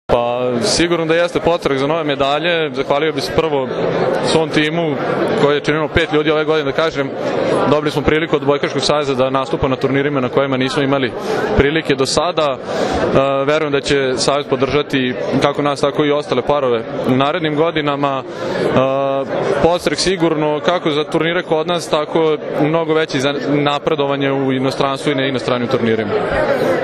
U beogradskom hotelu Metropol danas je održan tradicionalni Novogodišnji koktel Odbojkaškog saveza Srbije, na kojem su podeljenje nagrade najboljim pojedincima i trofeji “Odbojka spaja”.
IZJAVA